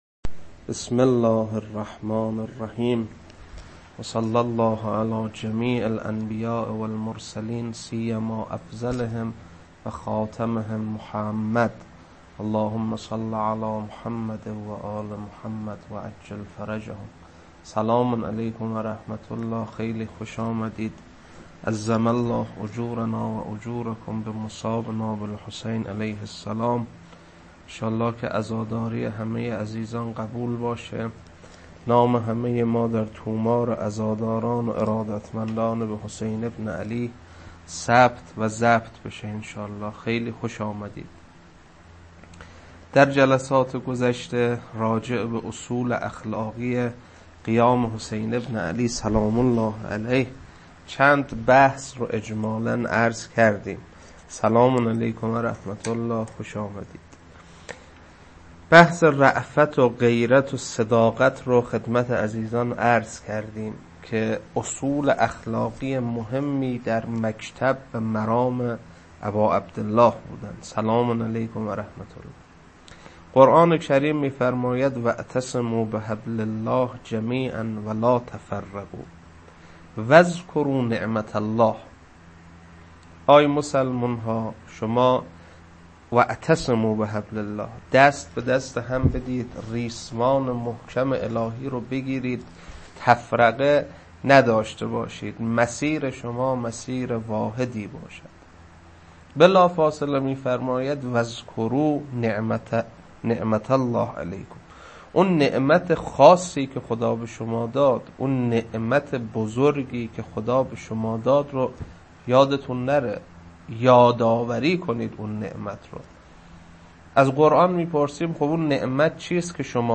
روضه عصر عاشورا.mp3
روضه-عصر-عاشورا.mp3